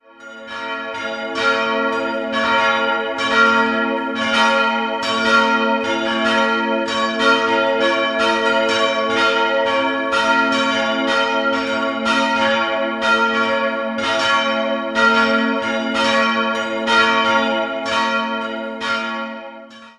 Das Gotteshaus wurde in den Jahren 1697 bis 1700 errichtet, nachdem der Vorgängerbau durch einen Blitzschlag stark beschädigt und danach abgetragen wurde. 3-stimmiges B-Moll-Geläute: b'-des''-f'' Alle Glocken wurden von Karl Czudnochowsky in Erding in Euphonlegierung gegossen, die beiden kleineren bereits 1950, die große kam 1952 hinzu.